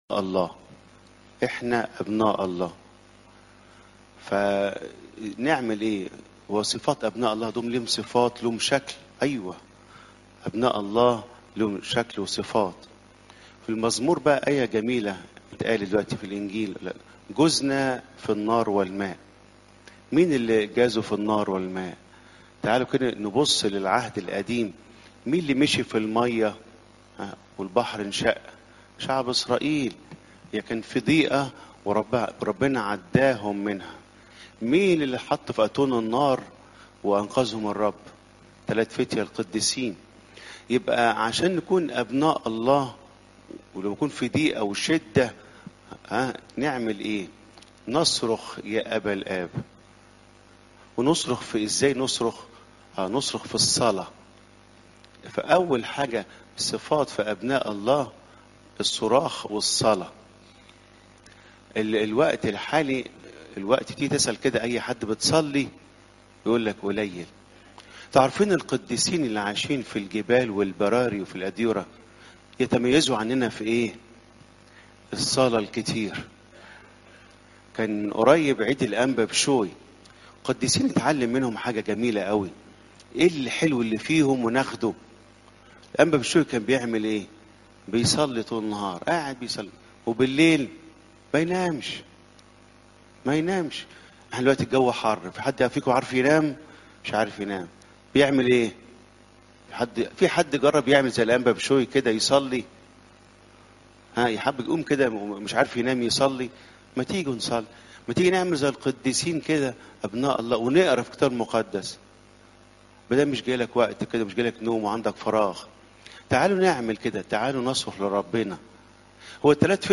عظات قداسات الكنيسة (لو 21 : 12 - 19)